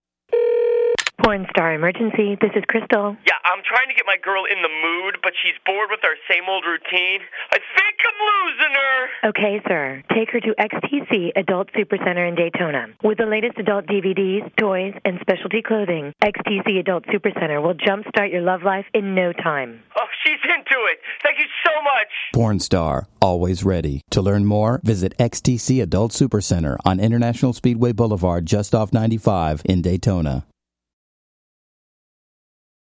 Medium Market Commercials are on tracks 16 through 20, and Large Market Commercials wrap it up on tracks 21 through 27.